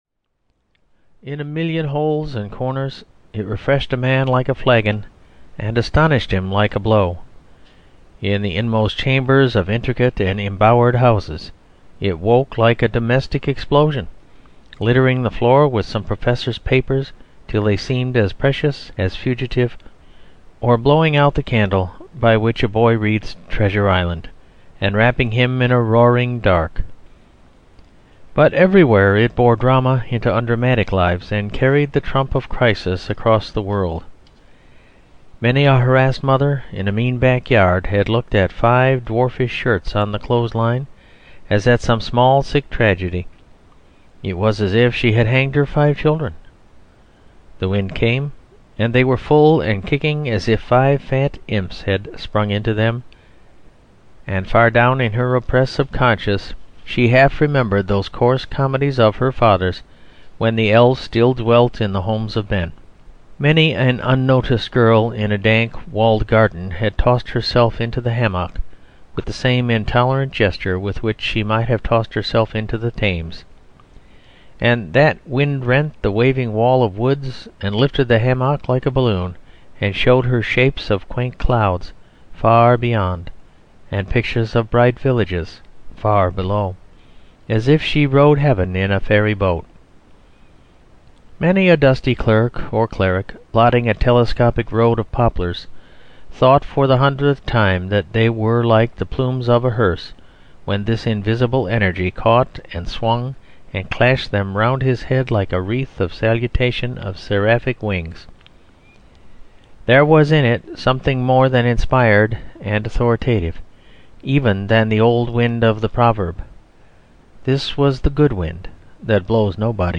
Manalive (EN) audiokniha
Ukázka z knihy